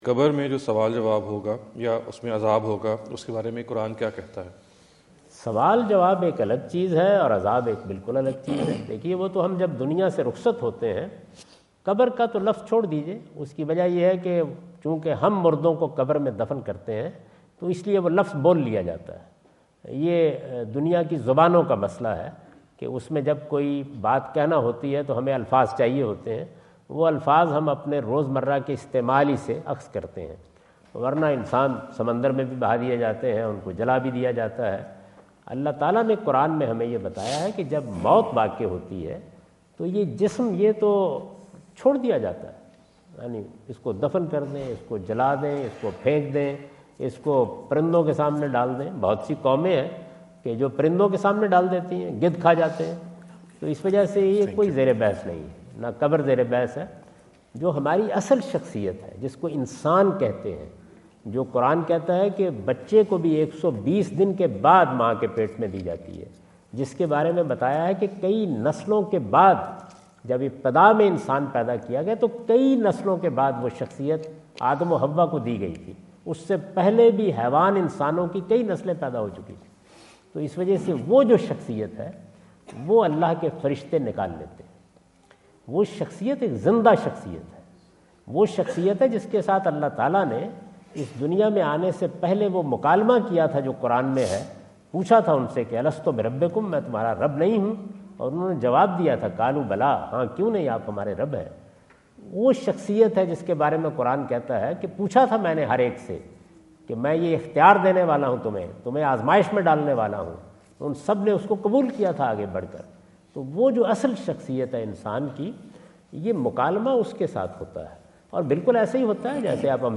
In this video Javed Ahmad Ghamidi answer the question about "what does Quran say about accountability in the grave? " asked at The University of Houston, Houston Texas on November 05,2017.
جاوید احمد صاحب غامدی دورہ امریکہ2017 کے دوران ہیوسٹن ٹیکساس میں "اسلام اور قران میں عذاب قبر کا کیا تصور ہے؟" سے متعلق ایک سوال کا جواب دے رہے ہیں۔